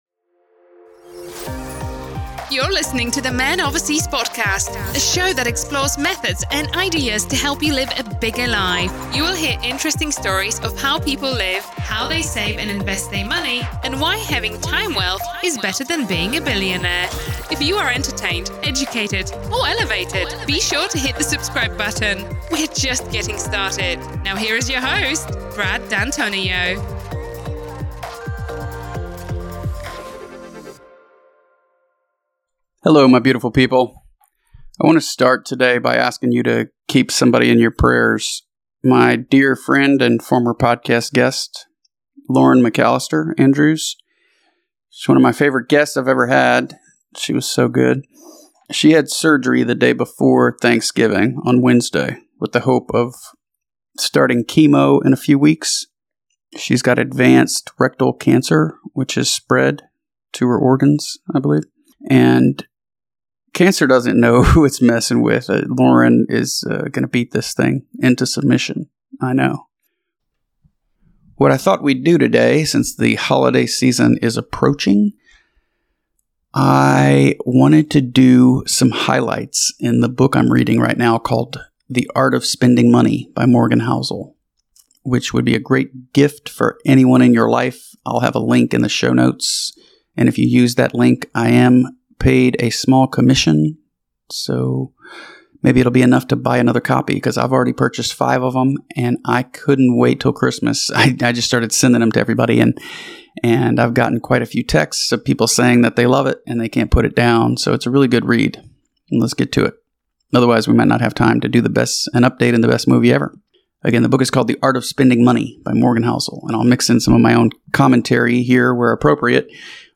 In this solo episode, I share highlights from The Art of Spending Money by Morgan Housel. I talk about getting what you want vs. what you need, how money shapes our independence, and why the book hit me at the right time.